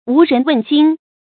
无人问津 wú rén wèn jīn 成语解释 津：渡口。
成语繁体 無人問津 成语简拼 wrwj 成语注音 ㄨˊ ㄖㄣˊ ㄨㄣˋ ㄐㄧㄣ 常用程度 常用成语 感情色彩 贬义成语 成语用法 兼语式；作宾语；含贬义 成语结构 联合式成语 产生年代 古代成语 成语正音 津，不能读作“jūn”。